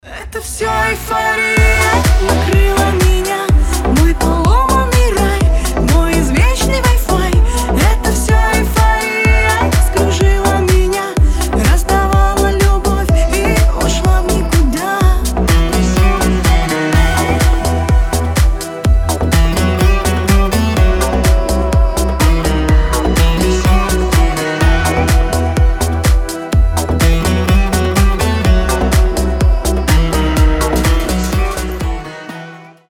• Качество: 320, Stereo
гитара
женский голос